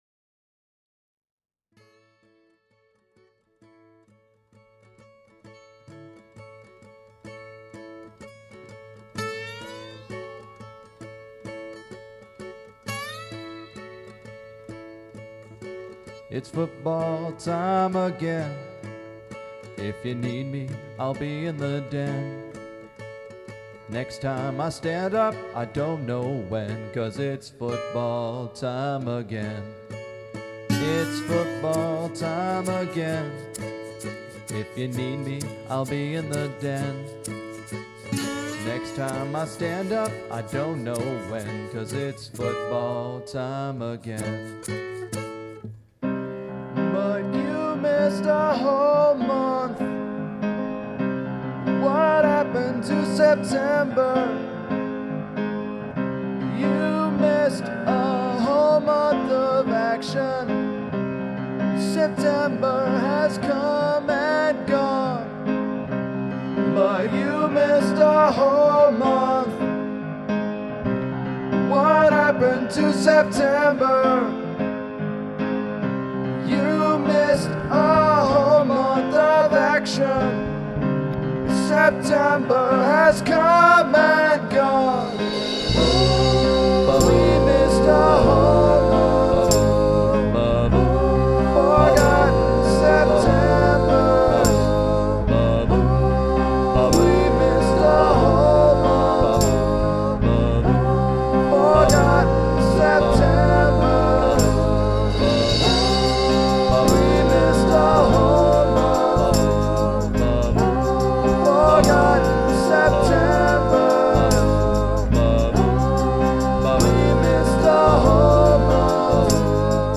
Here’s a jaunty little number (clearly broken up into three parts) to close out football week.
Production, mixing, all instruments by me.